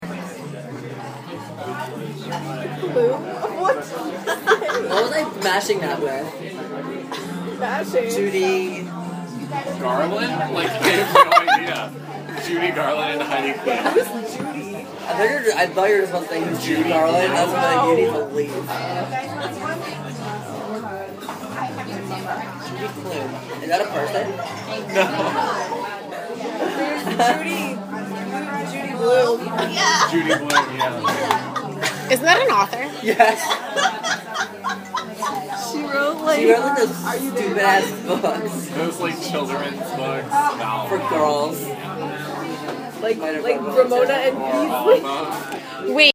Field Recording #5
Sounds: People talking, laughing, music, silverware clinking, cups clinking